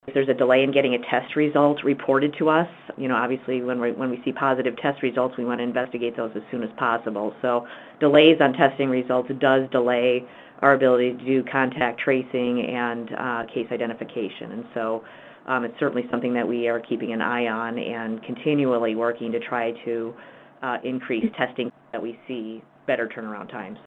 Malsam-Rysdon says contact tracing can’t begin until test results are known.